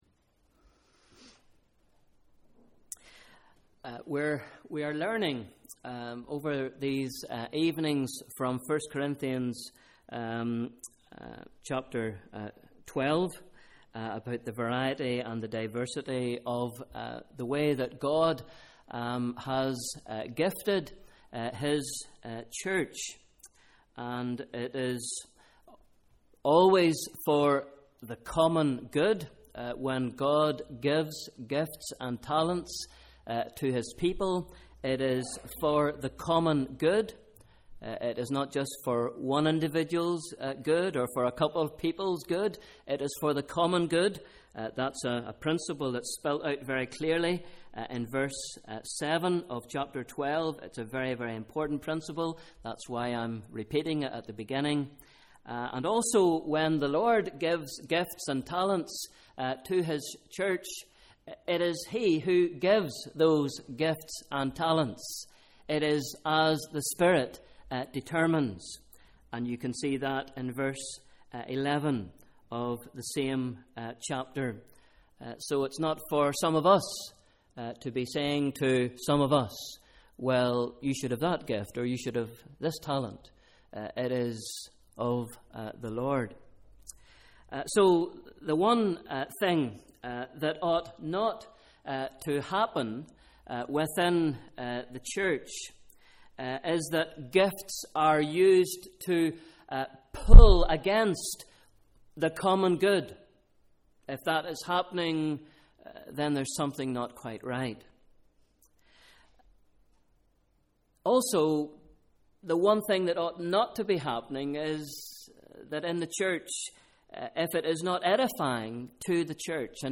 Evening Service – Sunday 16th March 2014 Bible Reading: 1 Corinthians 12 v 12-31